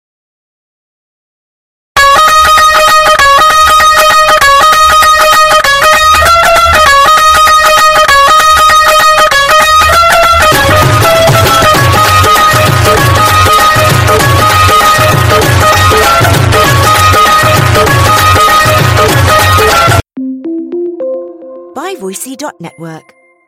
Indian Very Loud Music